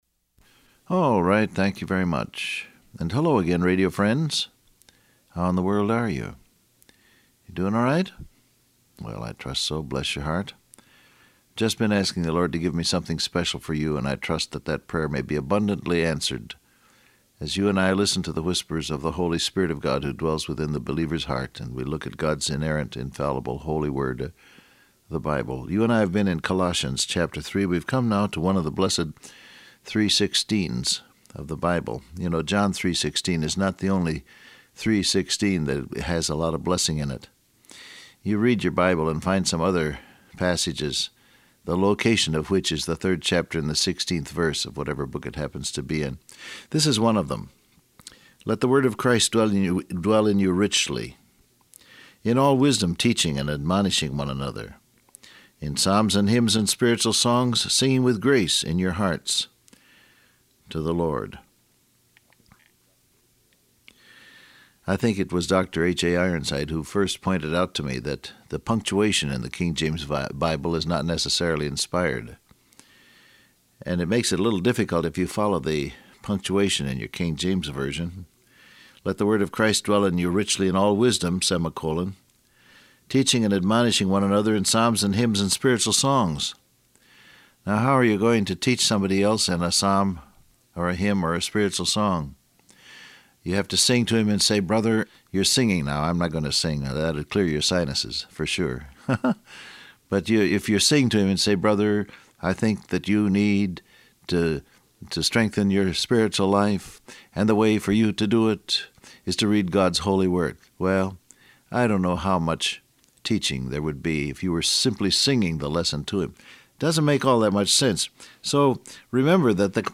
Download Audio Print Broadcast #1914 Scripture: Colossians 3:16 , Ephesians 5:18 Transcript Facebook Twitter WhatsApp Alright, thank you very much.